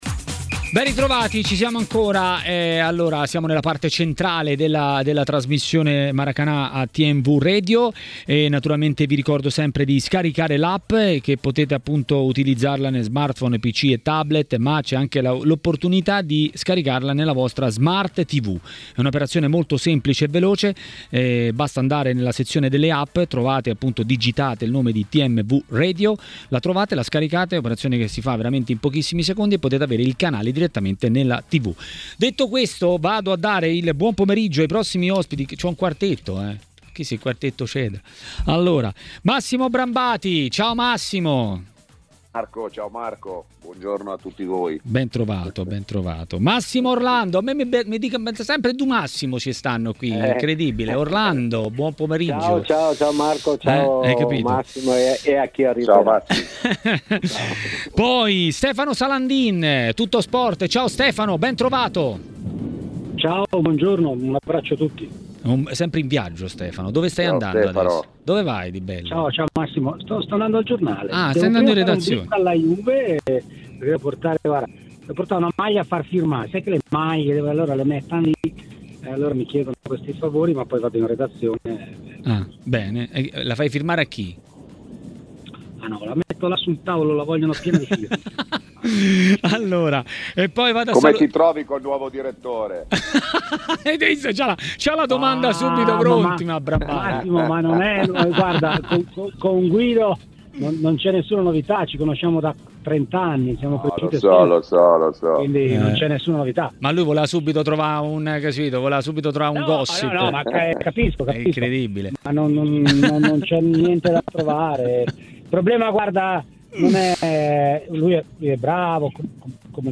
A Maracanà, trasmissione di TMW Radio, l'ex calciatore Massimo Orlando ha parlato dei temi della Serie A.